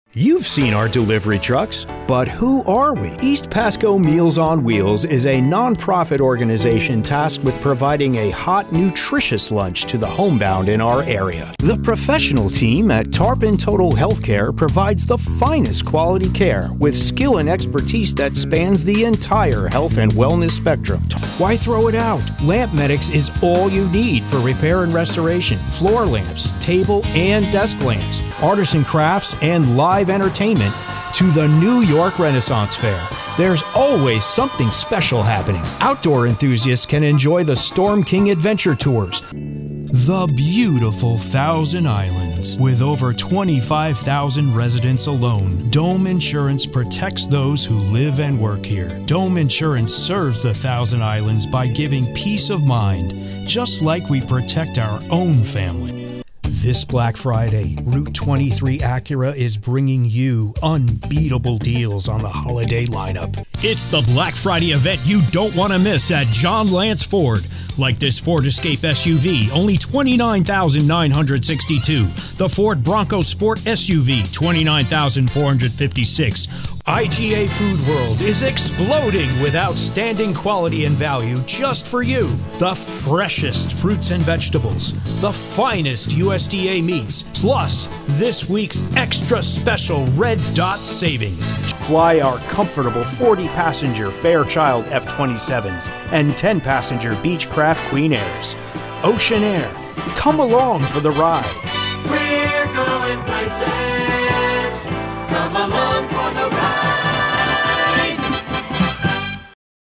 Commercial montage for radio and television
I record in a acoustic treated room with a top-shelf mic (Sennheiser 416 shotgun condensor) into a Universal Apollo Twin set up with an API Vision console, an Avalon VT-737, a UAD 1176 Rev A compressor, and the old standard Teletronix LA2A.